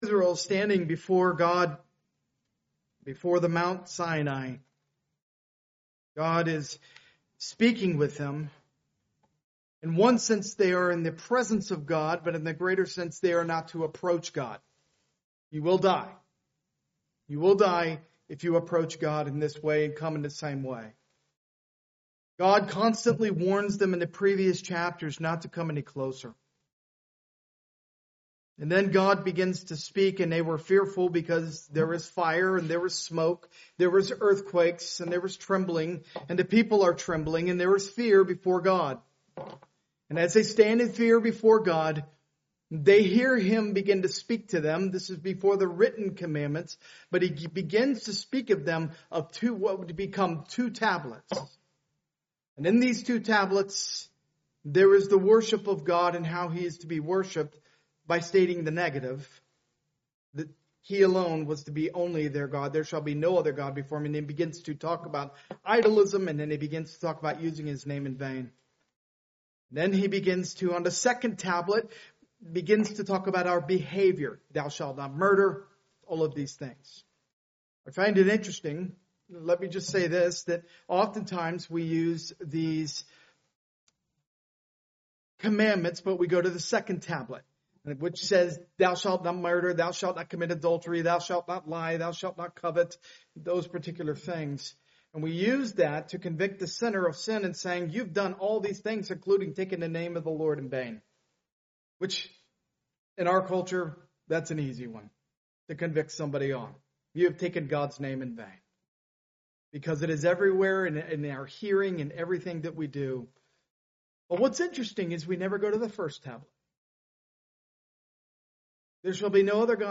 Part of the The Book of Exodus series, preached at a Morning Service service.